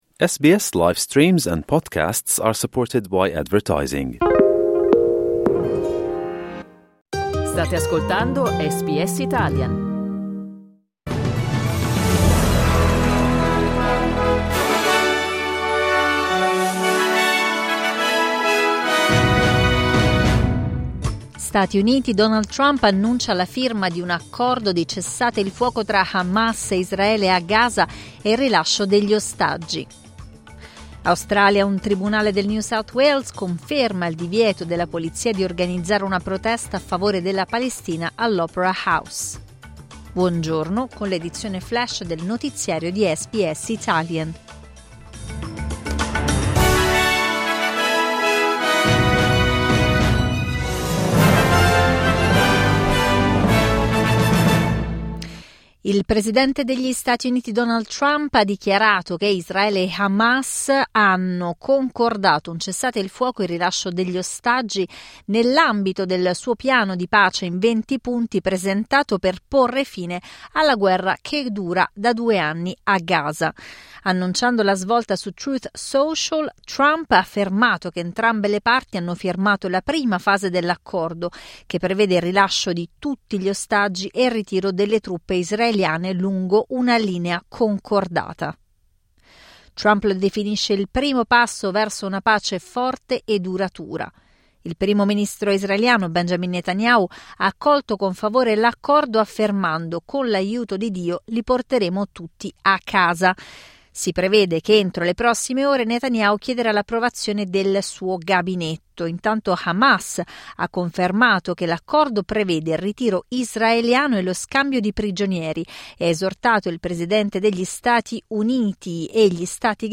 News flash giovedì 9 ottobre 2025